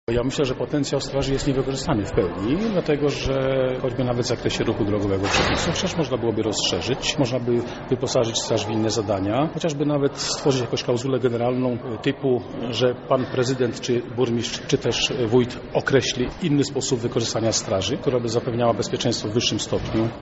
O potencjale straży miejskiej mówi komendant